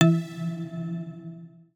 Universal UI SFX / Clicks
UIClick_Long Modern Ringing 01.wav